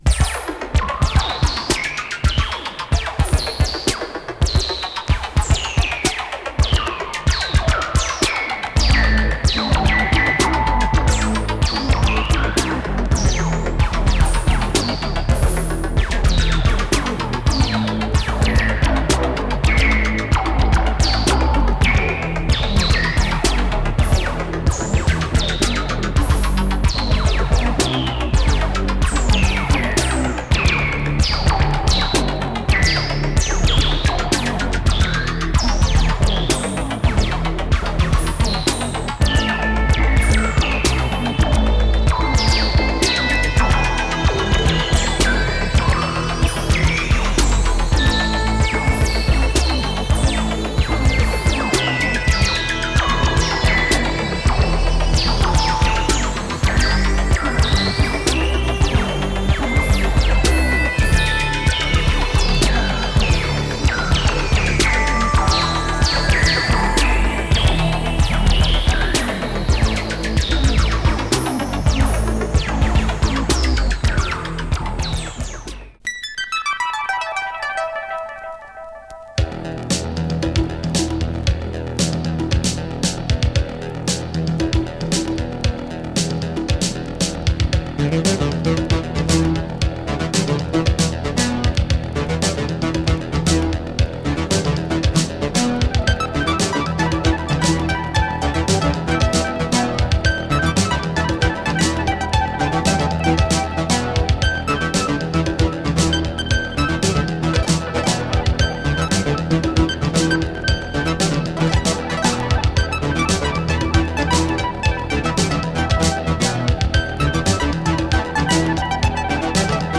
Heavy UK electro funk & breaks !